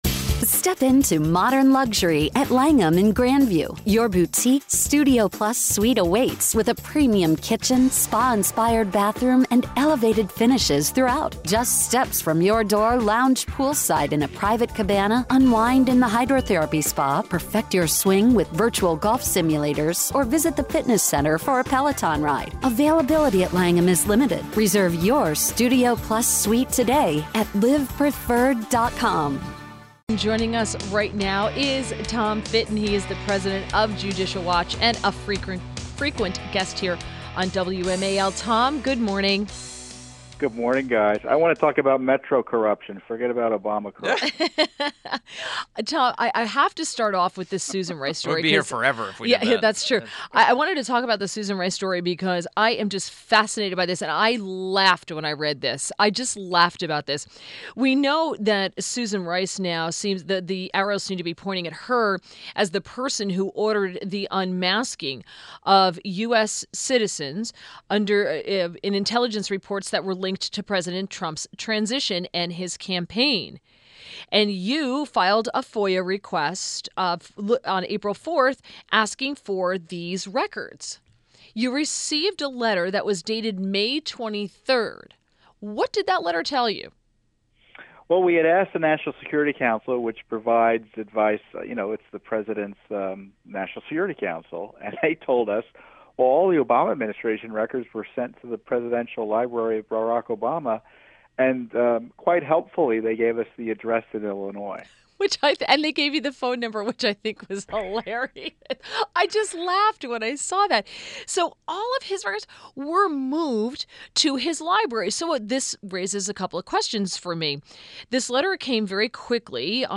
WMAL Interview - TOM FITTON 06.21.17